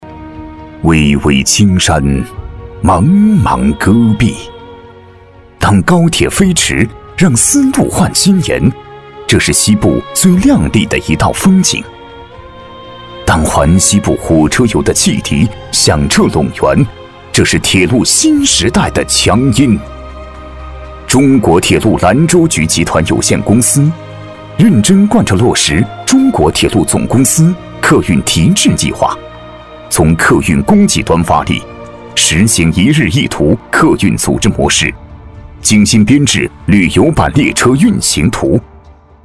旅游宣传片男135号（铁路
自然诉说 旅游风光
大气震撼，磁性稳重男音，低沉讲述、专题汇报、宣传片、纪录片题材。